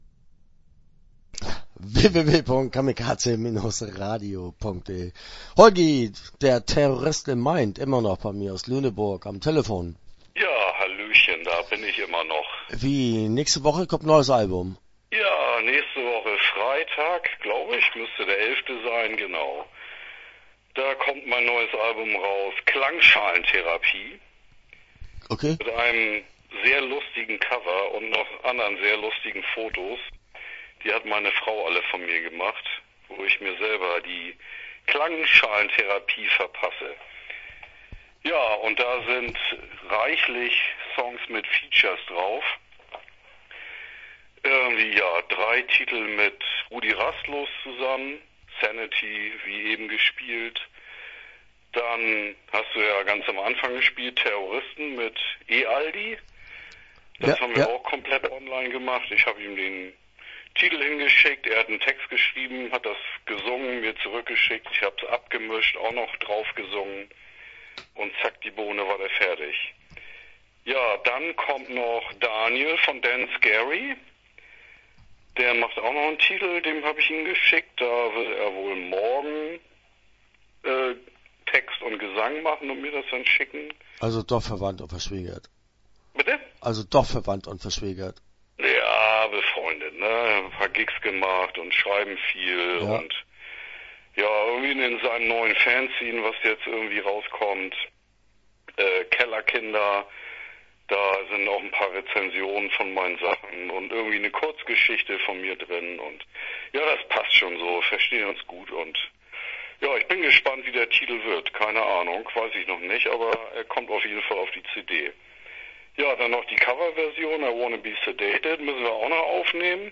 Terrorist In Mind - Interview Teil 1 (11:55)